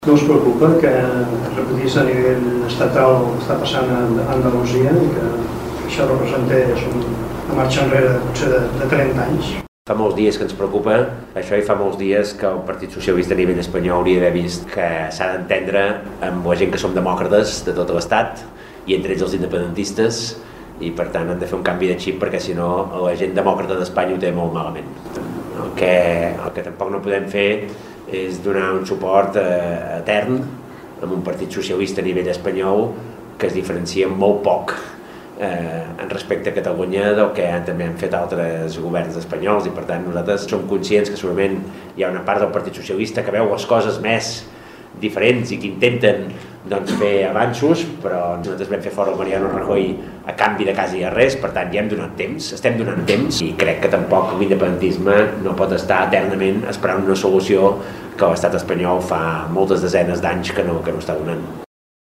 Al final del acte ha respòs preguntes de la premsa sobre política nacional i estatal
I finalment a pregunta de Ràdio Capital